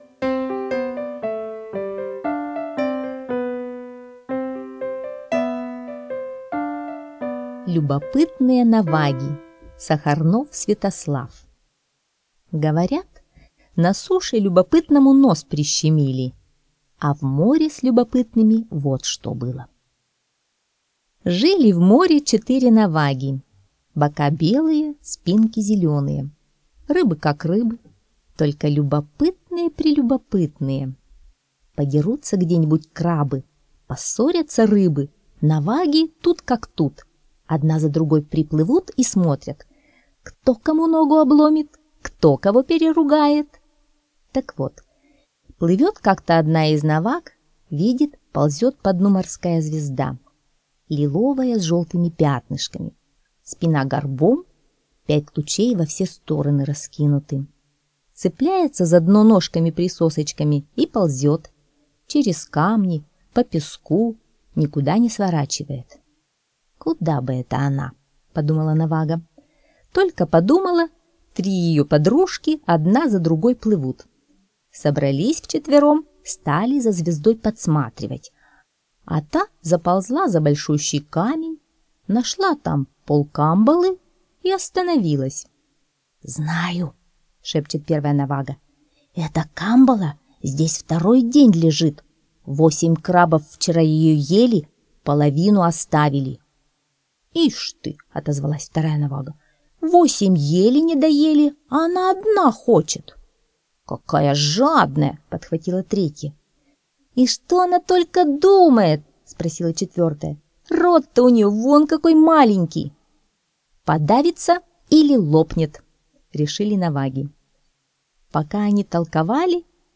Любопытные наваги — аудиосказка Сахарнова С.В. Слушайте сказку «Любопытные наваги» онлайн на сайте Мишкины книжки.